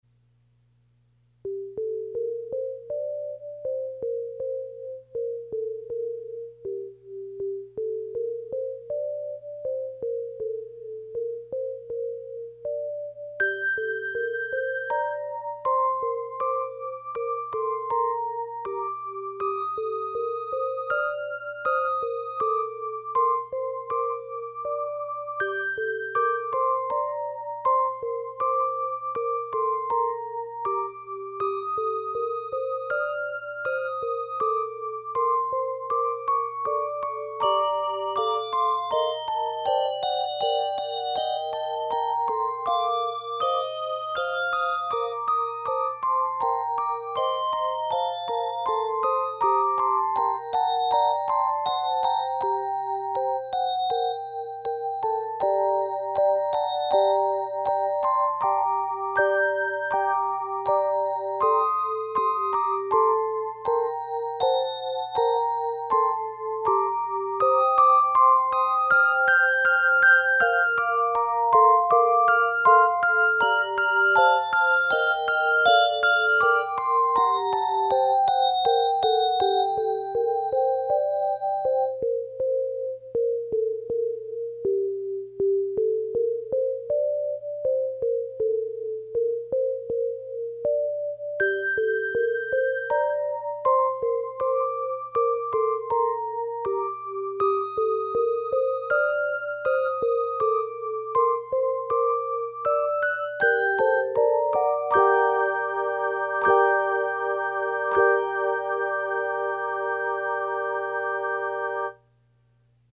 It is 37 measures in length and is scored in g minor.